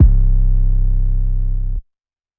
808 (Metro)_Hard.wav